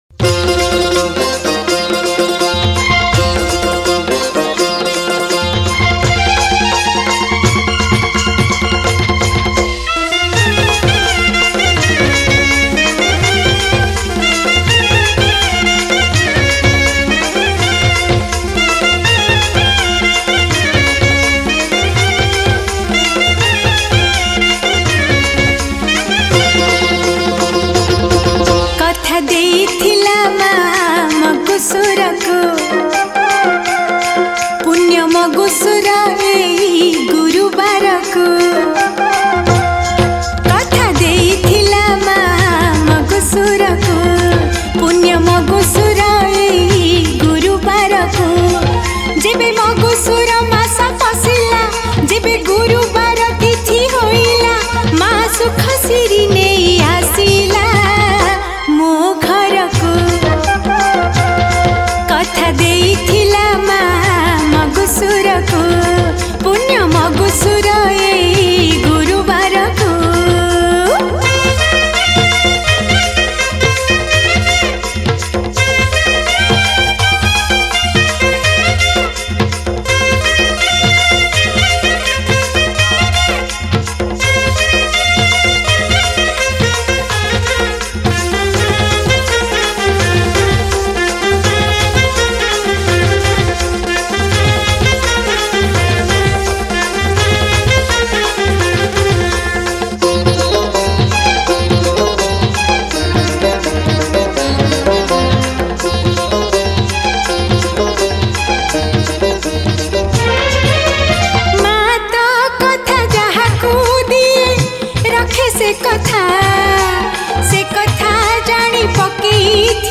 Category : Manabasa Gurubara Bhajan